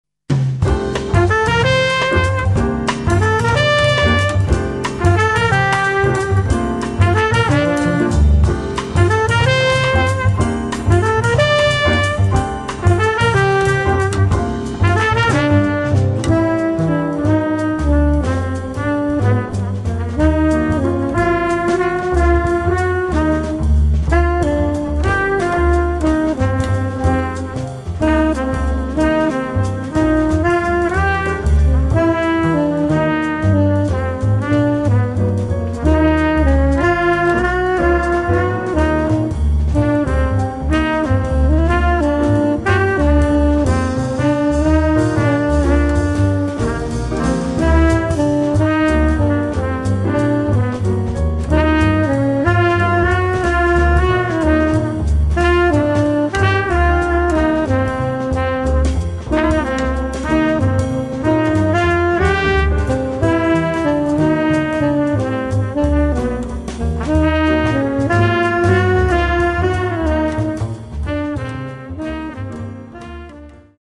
trumpet e flugelhorn
piano
drums and vibraphone